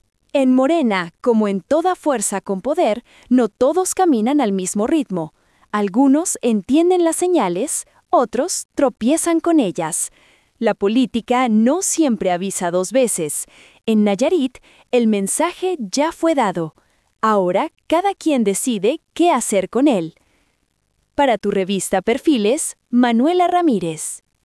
🎙 COMENTARIO EDITORIAL